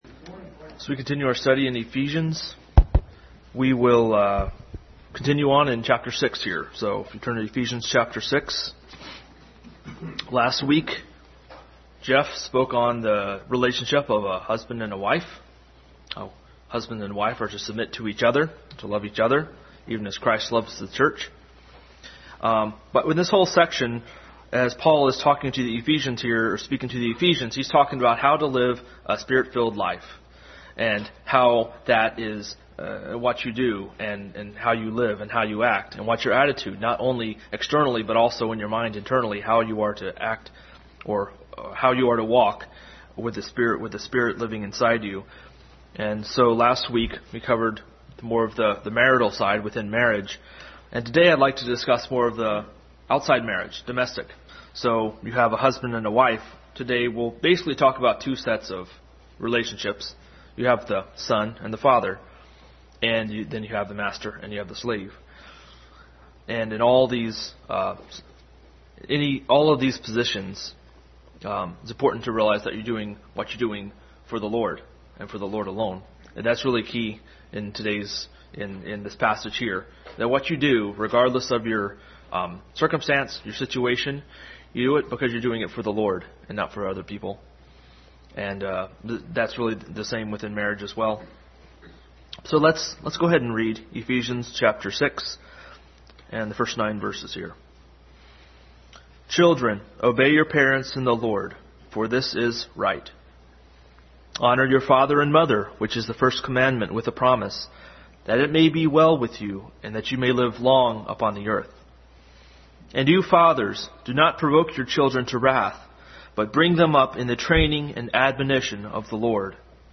Adult Sunday School continued study in Ephesians.
Matthew 25:31 Service Type: Sunday School Adult Sunday School continued study in Ephesians.